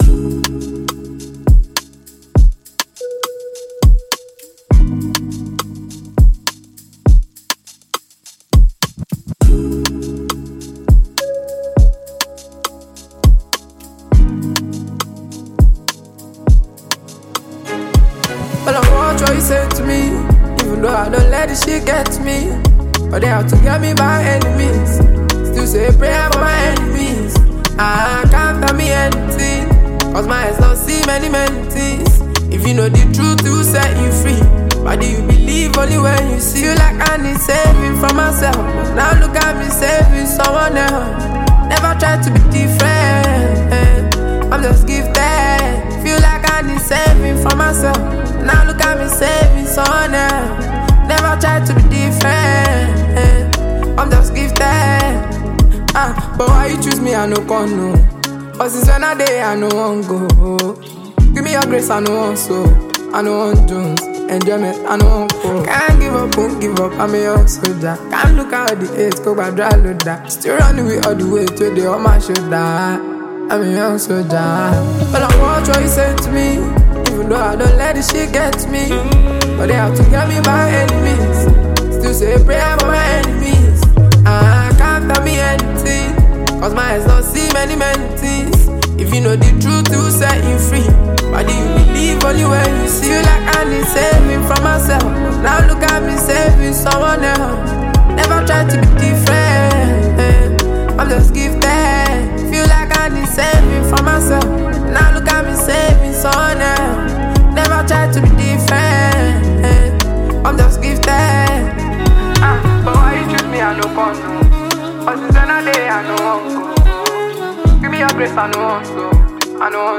Renowned Nigerian Afrobeats talent and performer
an inspiring vibe
The music scene is excited to embrace this energetic release